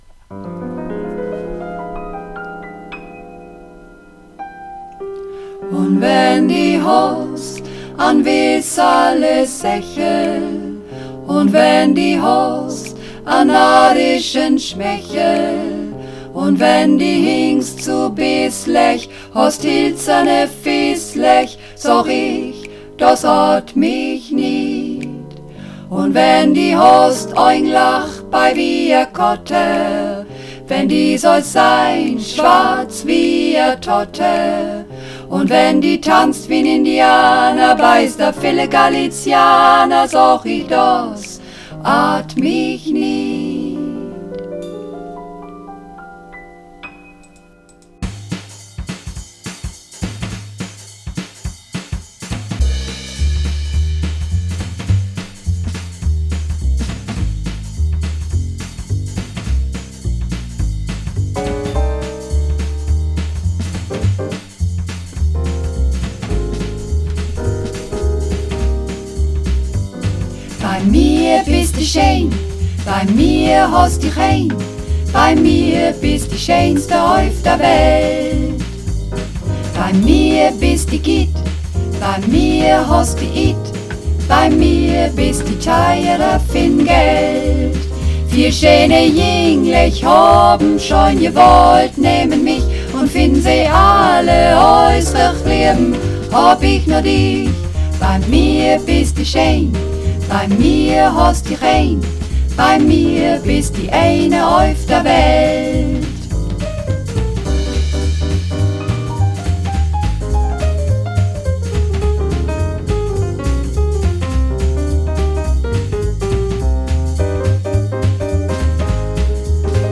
Runterladen (Mit rechter Maustaste anklicken, Menübefehl auswählen)   Bei mir bistu shein (Mehrstimmig)
Bei_mir_bistu_shein__4_Mehrstimmig.mp3